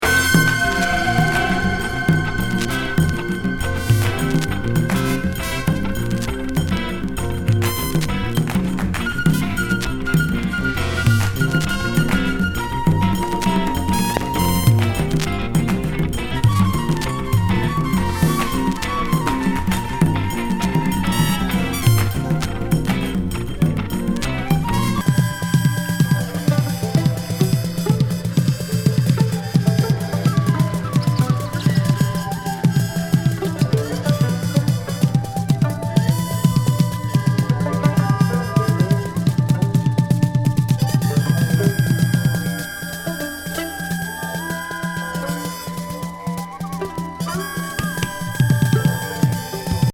チベタン・ムード